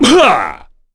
Ricardo-Vox_Landing.wav